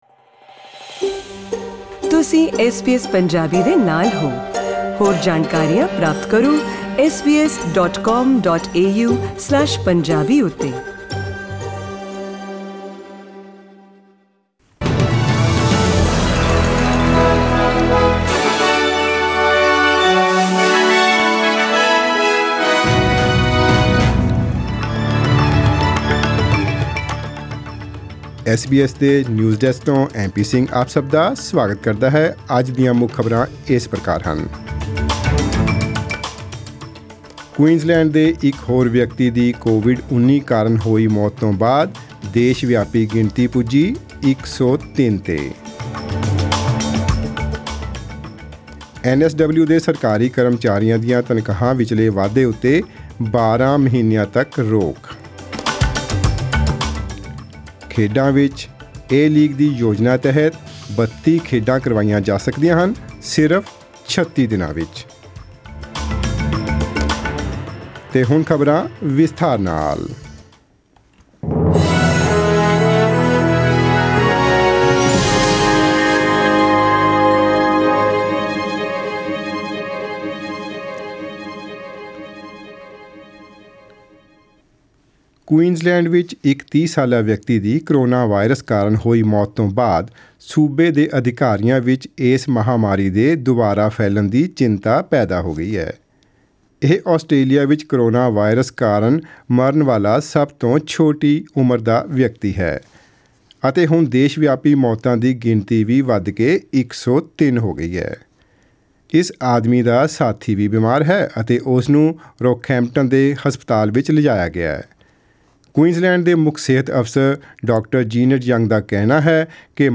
In today’s news bulletin